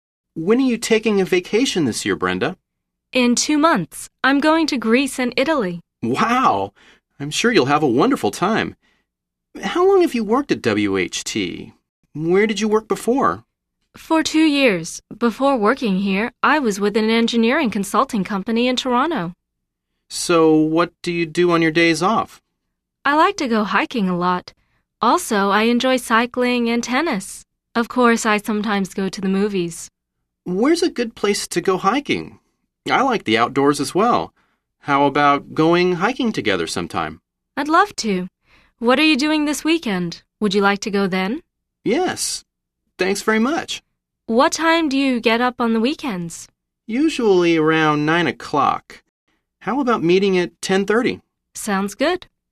實戰對話→